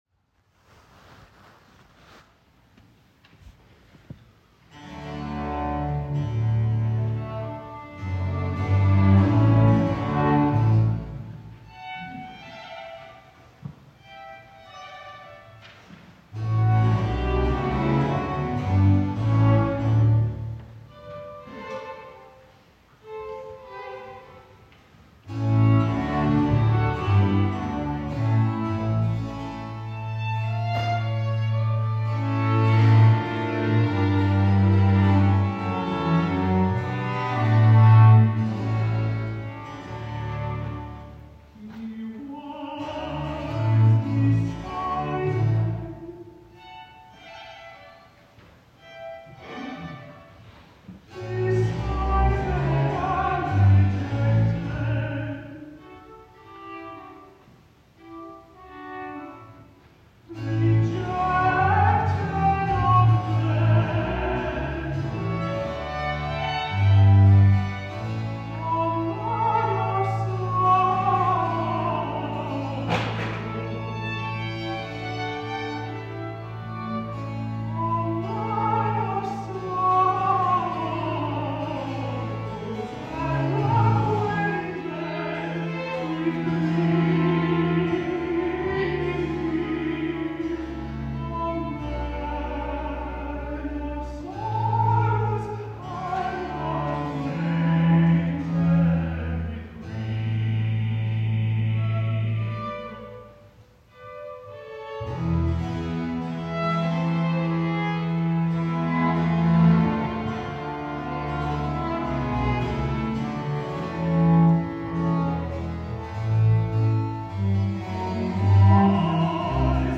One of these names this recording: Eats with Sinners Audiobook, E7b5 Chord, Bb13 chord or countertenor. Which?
countertenor